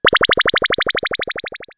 UFO05.wav